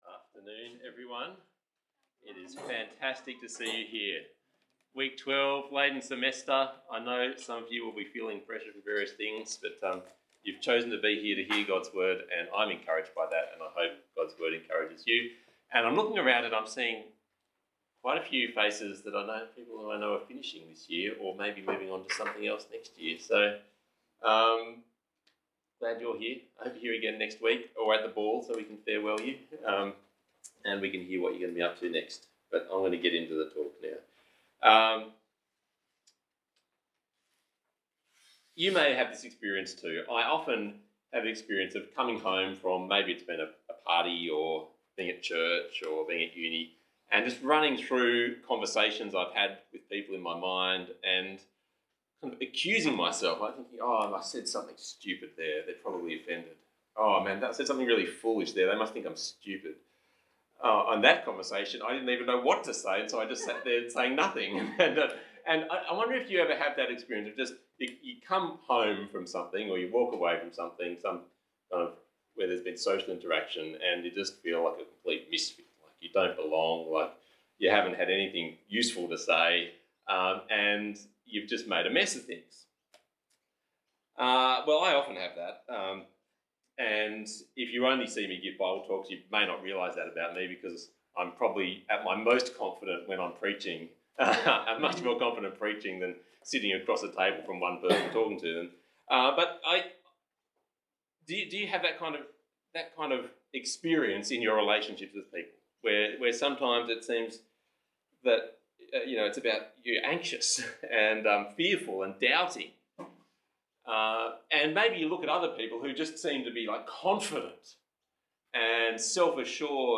Bible Talk